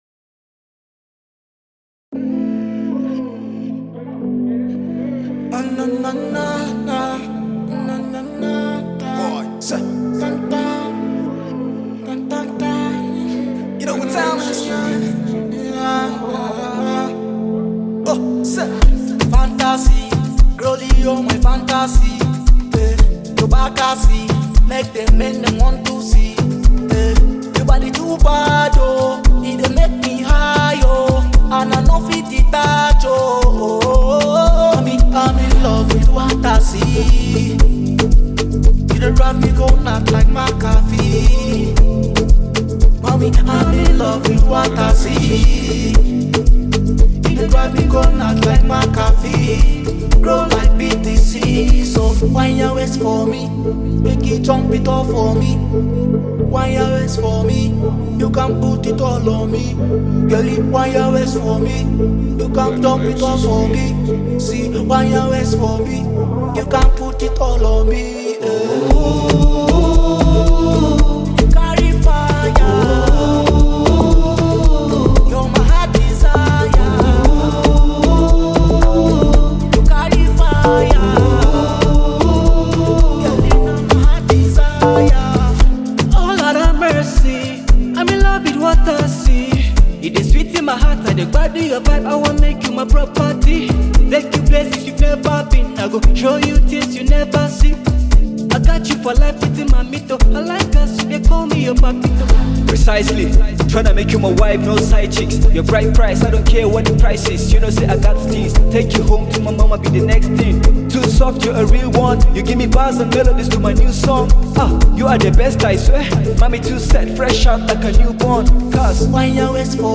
Nigerian sensational singer
smooth vocals and infectious melody